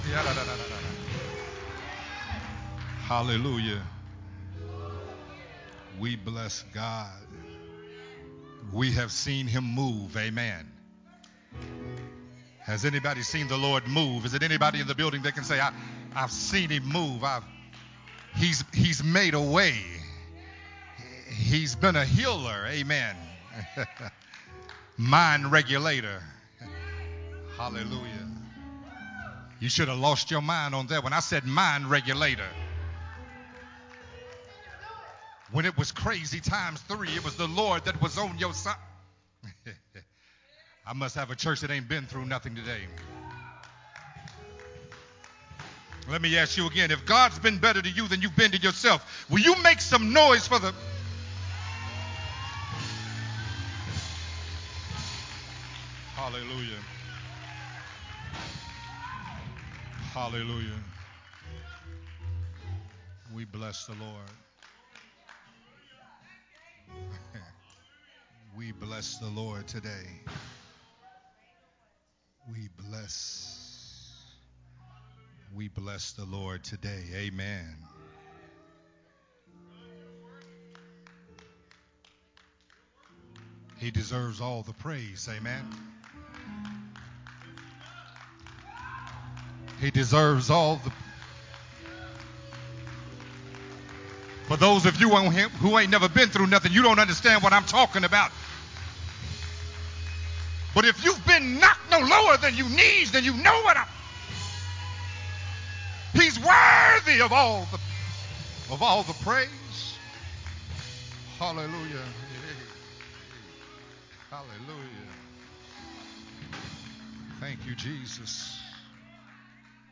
Part 4 of “The Crossroads” sermon series
recorded at Unity Worship Center on August 28, 2022.